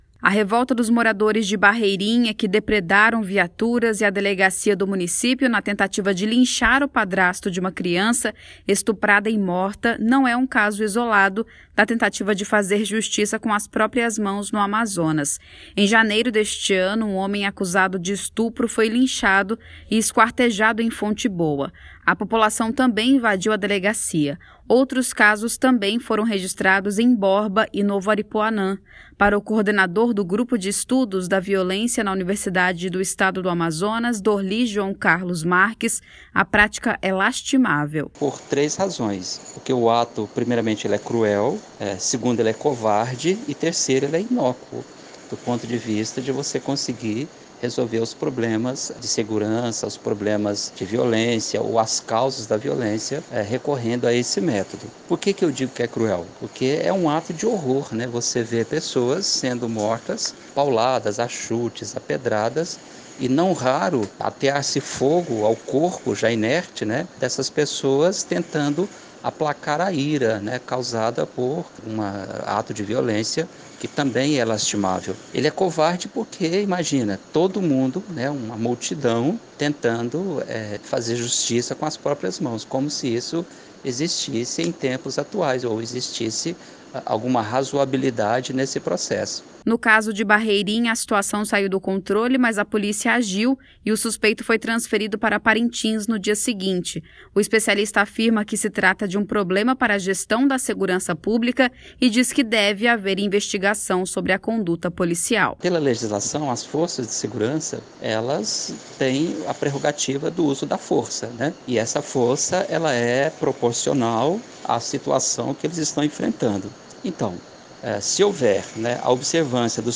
O especialista também avalia a conduta policial, após a morte de duas pessoas durante o protesto. Ouça a reportagem: